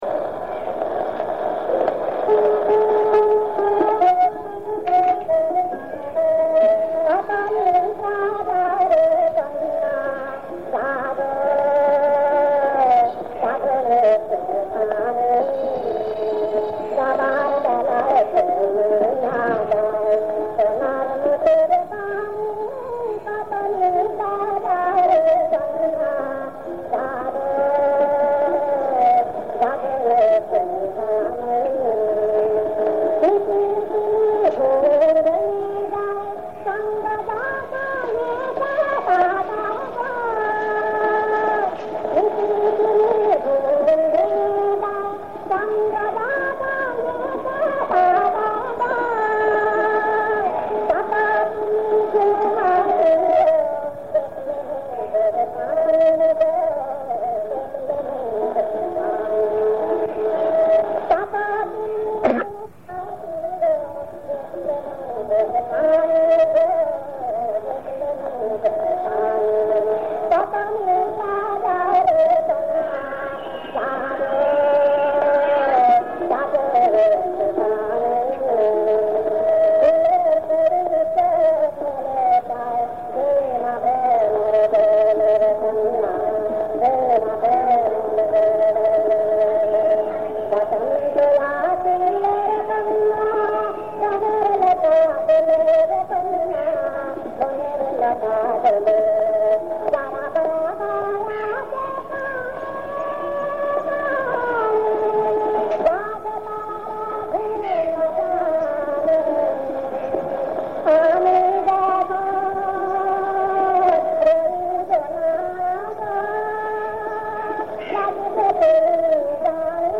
• সুরাঙ্গ: মিশ্র ভাটিয়ালি
• তাল: কাহারবা